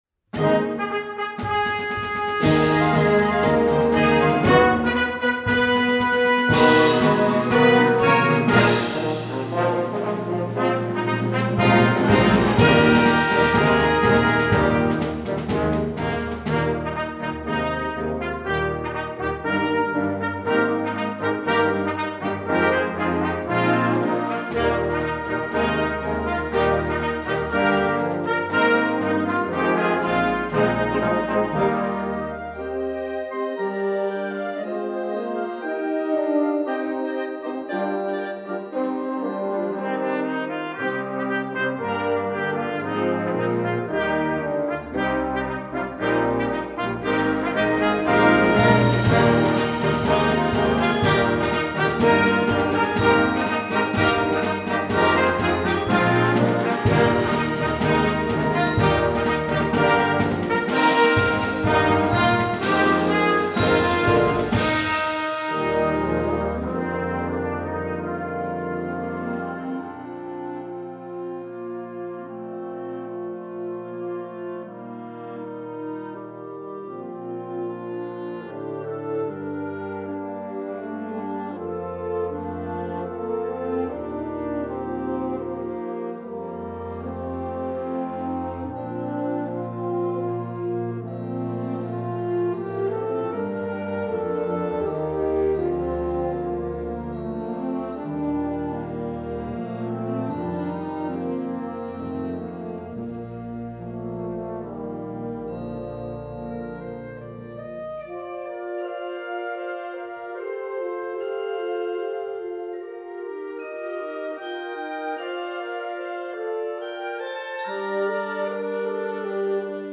Ouvertüre für Jugendblasorchester
Besetzung: Blasorchester